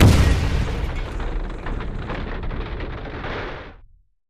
Incoming artillery with explosion and debris.